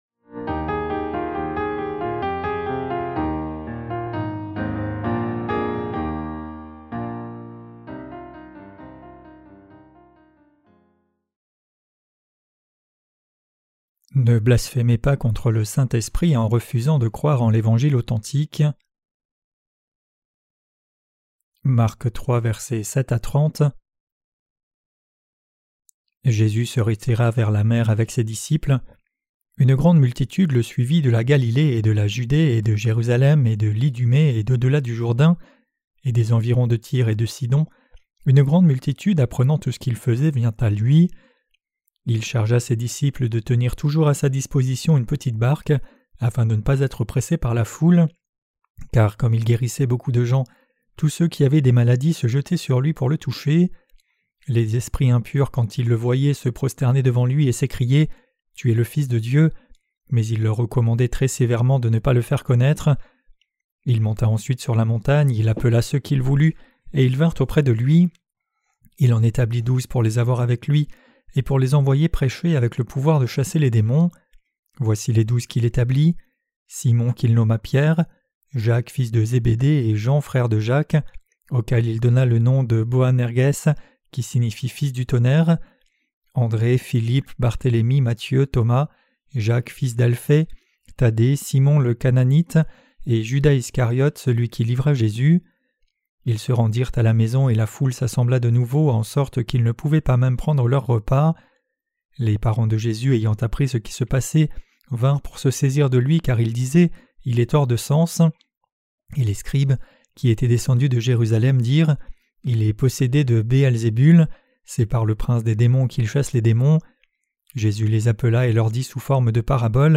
Sermons sur l’Evangile de Marc (Ⅰ) - QUE DEVRIONS-NOUS NOUS EFFORCER DE CROIRE ET PRÊCHER? 7.